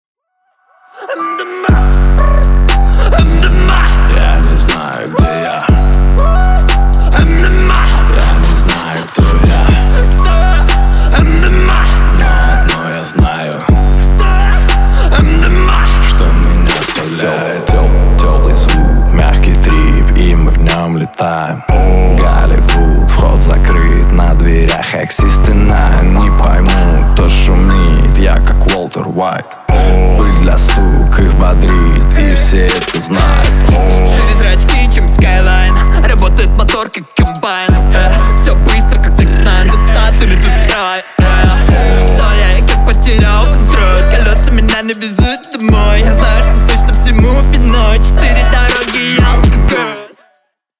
• Качество: 320, Stereo
русский рэп
мощные басы
качающие